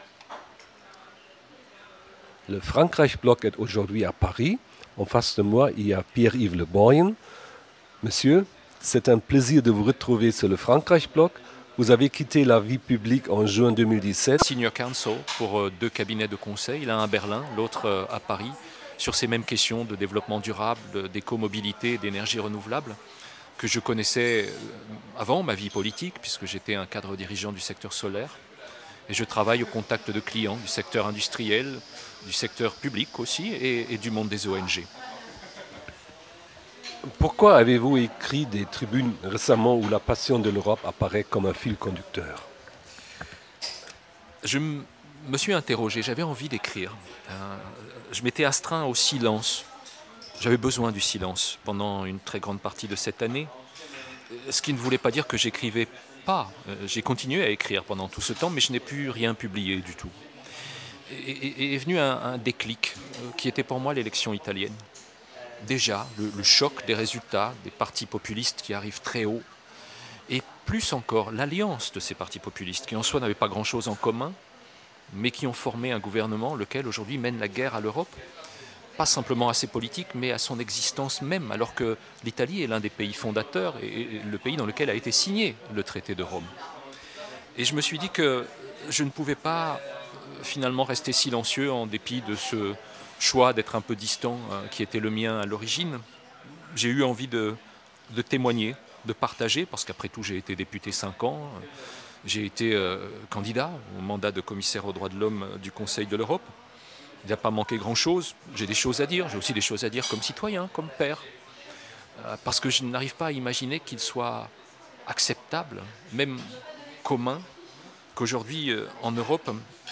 Diese Mal haben wir in einem kleinen Bistrot gegenüber von Sciences Po lange über Europa, die Vorschläge von Präsident Emmanuel Macron ( > Emmanuel Macron und Europa ) und die Reaktionen in Deutschland gesprochen. Wir beide sind uns einig, dass das Wiederstarken des Nationalismus und die Stärke der rechtsextremen Parteien, unter anderem, einer fehlenden Pädagogik im Dienste Europas seitens der europäischen Politiker geschuldet sind.
Interview Pierre Le Borgn‘